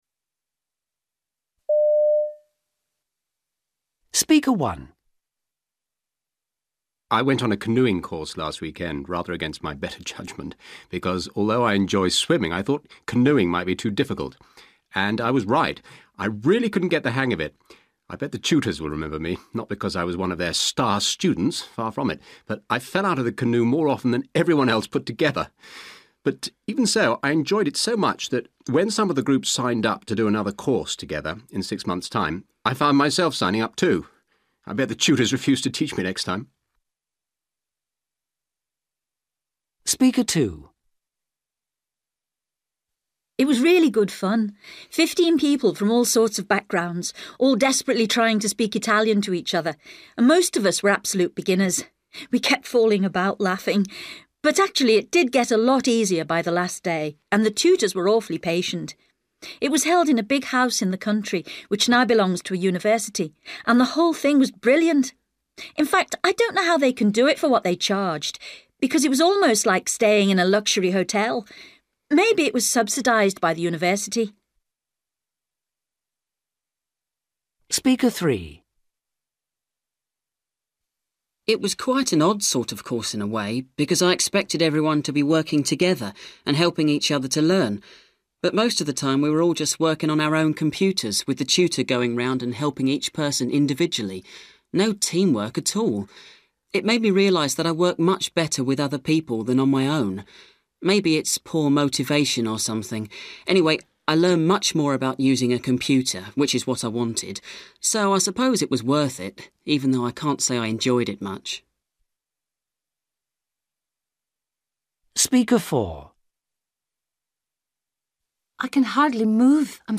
You will hear five different people talking about short courses they have attended.